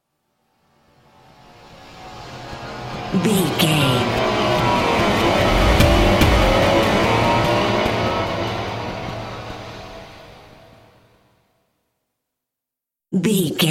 Thriller
Aeolian/Minor
synthesiser
drum machine
tension
ominous
dark
haunting
creepy
spooky